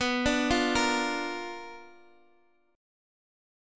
BmM7b5 chord